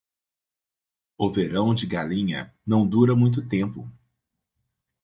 Pronounced as (IPA)
/ɡaˈlĩ.ɲɐ/